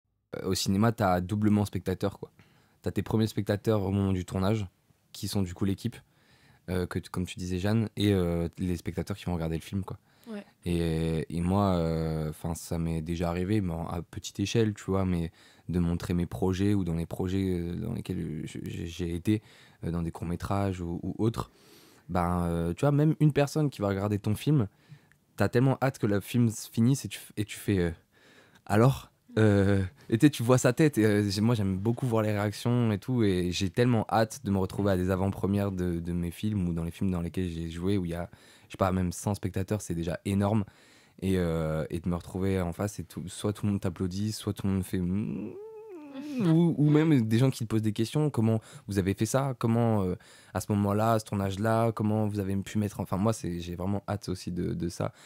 21 - 38 ans - Baryton